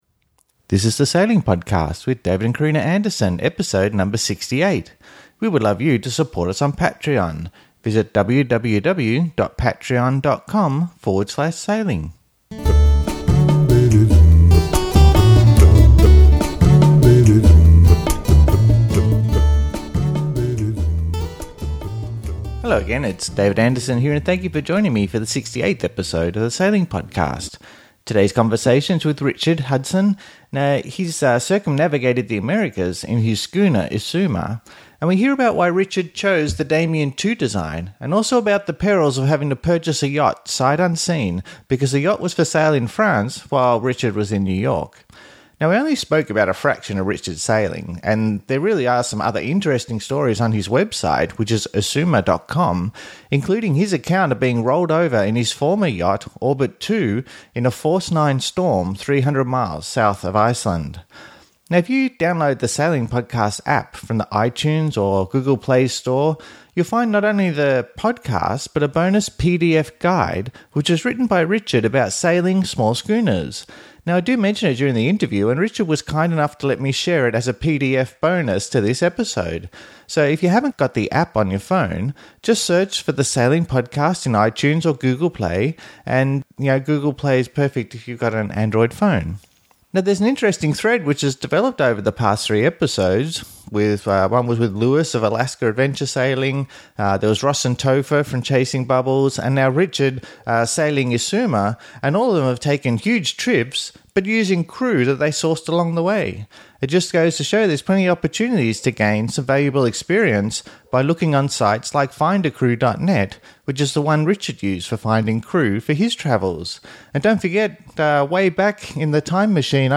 The Sailing Podcast, Audio Interview